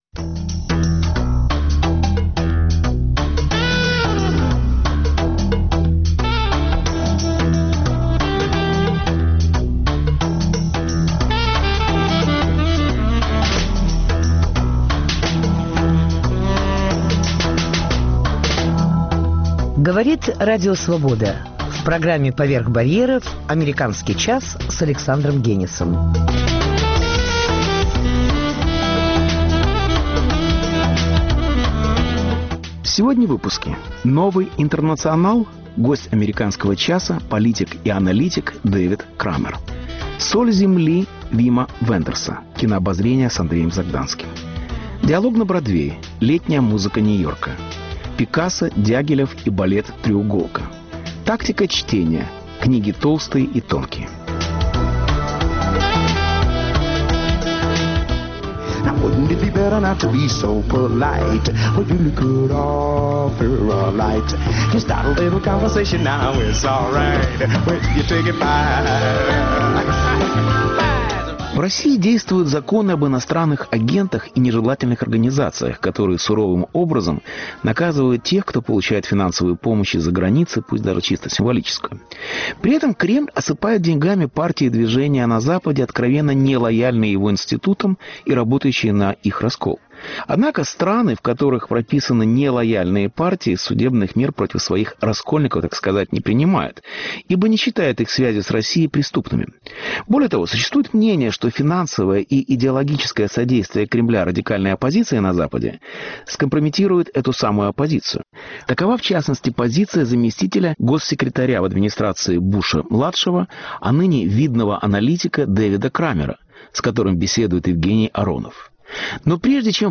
Embed Новый Интернационал? Гость АЧ - политик и аналитик Дэвид Крамер